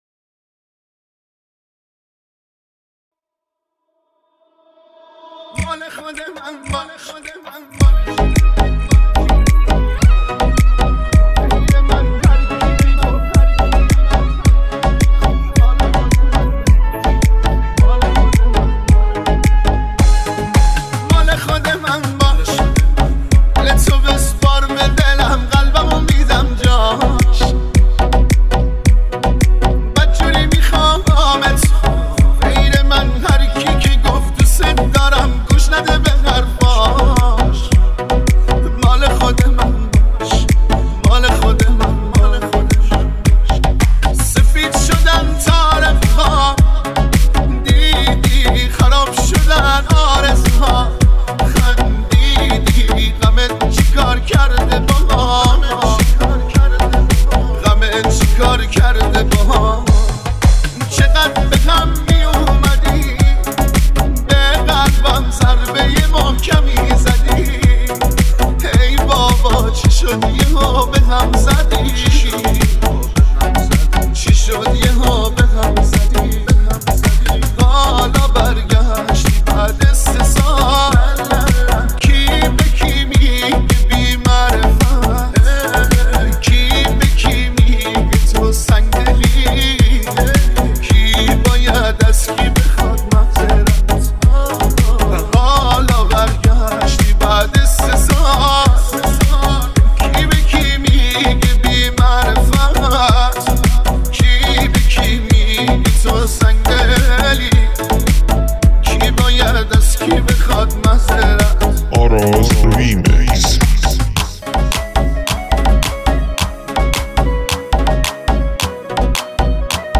ریمیکس پنجم